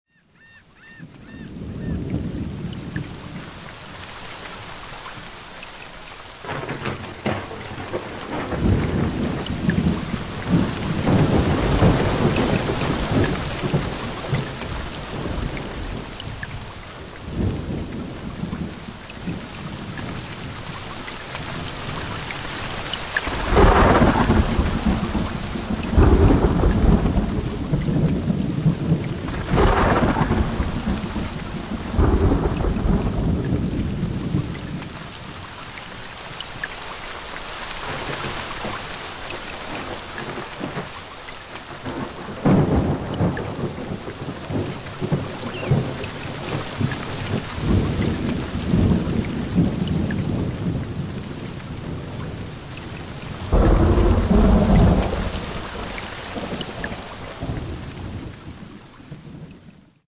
Tropical Storm
Category: Animals/Nature   Right: Personal